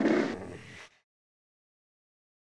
PixelPerfectionCE/assets/minecraft/sounds/mob/polarbear_baby/idle3.ogg at mc116